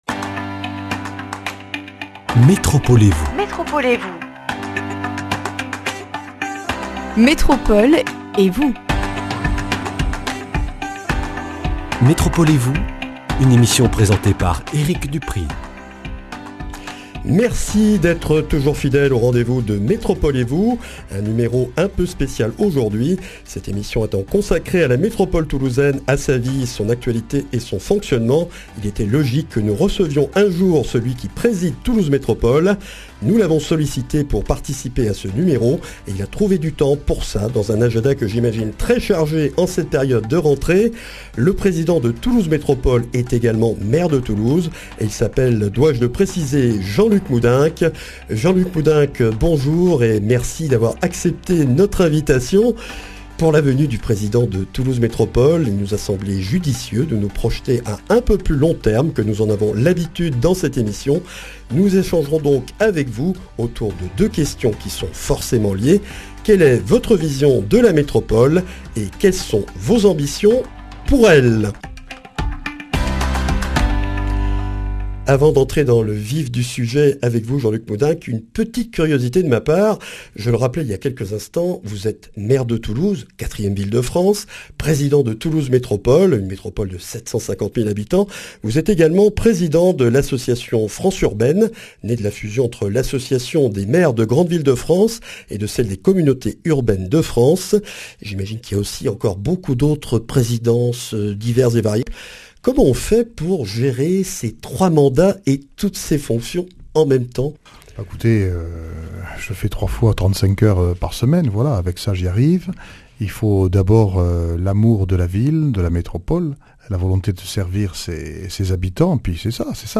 Une émission avec Jean-Luc Moudenc, maire de Toulouse et président de Toulouse Métropole, pour en savoir plus sur sa vision de la métropole toulousaine et les ambitions qu’il a pour elle.
Speech Quelle est la vision de la Métropole de Jean-Luc Moudenc ?